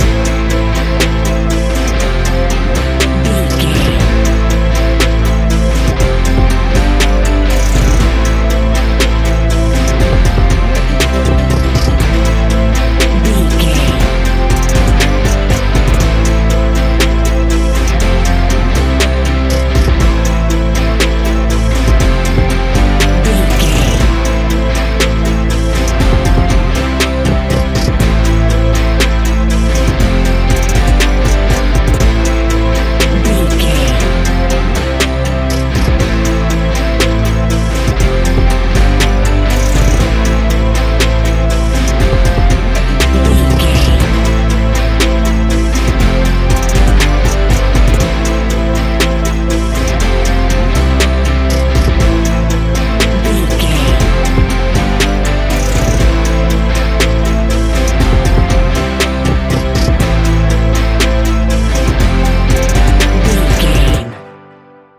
Ionian/Major
A♭
ambient
new age
downtempo